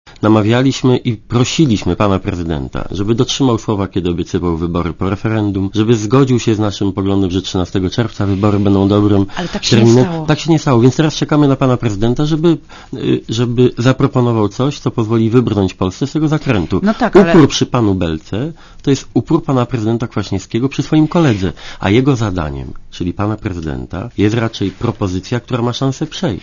Lider Platformy Obywatelskiej był dzisiaj gościem Radia ZET.
Posłuchaj komentarza Donalda Tuska